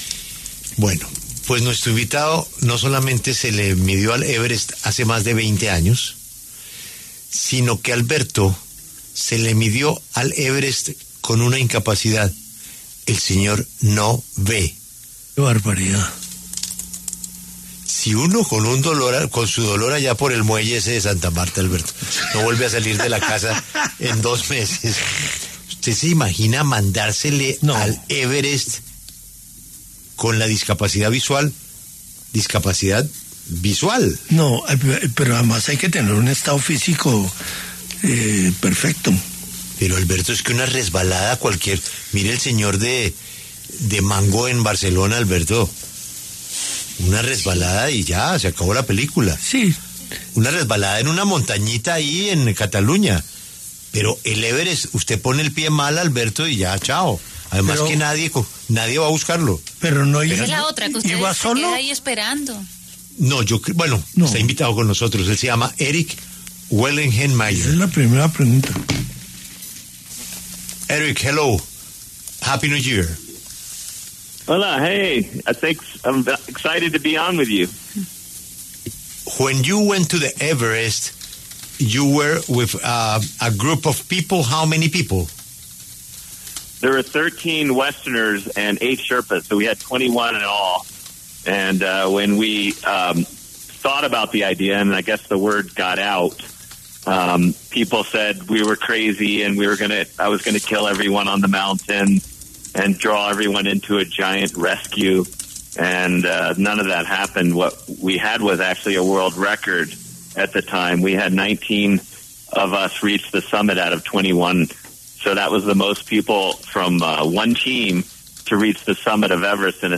El atleta Erik Weihenmayer, primera persona invidente en escalar el monte Everest, conversó con La W a propósito del cortometraje ‘Soundscape’ que relató su hazaña lograda hace más de 20 años.